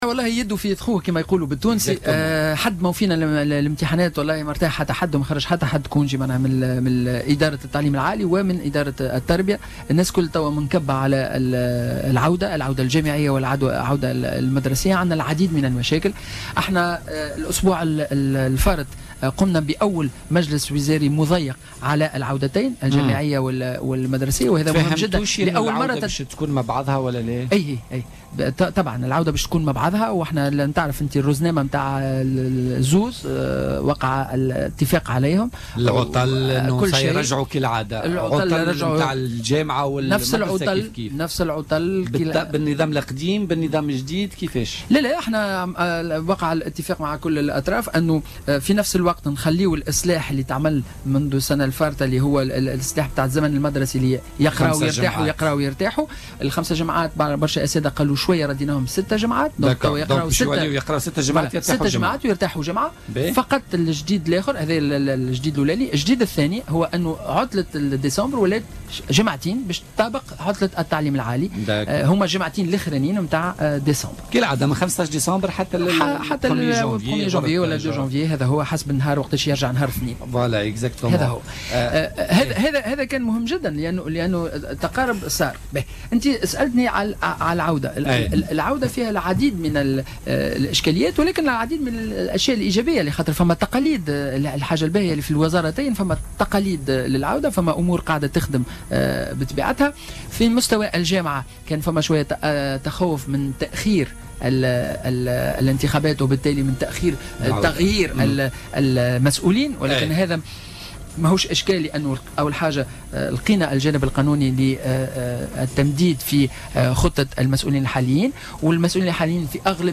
وأضاف خلبوس، ضيف برنامج "بوليتيكا" اليوم أن جديد العودة المدرسية هذه السنة هو اعتماد 6 أسابيع دراسة يليها أسبوع راحة، إضافة إلى تطابق عطلة شهر ديسمبر بالنسبة للتلاميذ مع عطلة التعليم العالي.